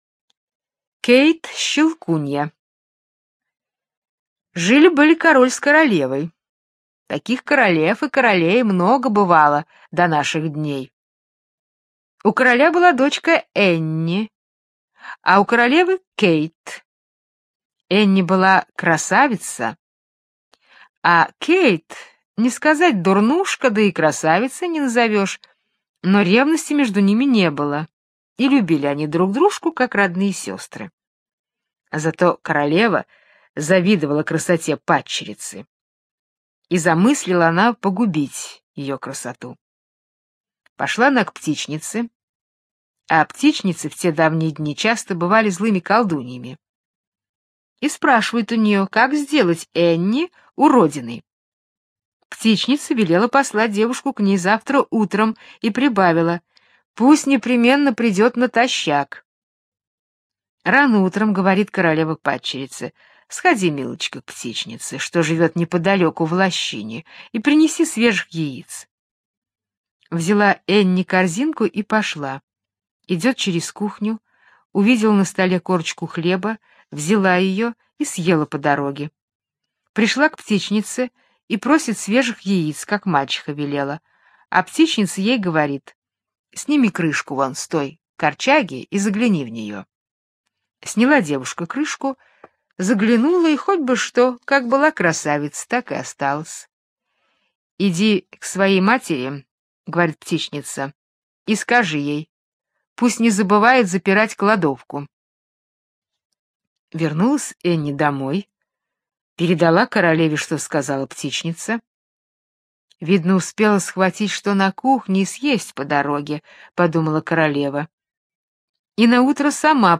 Кейт-щелкунья - британская аудиосказка - слушать онлайн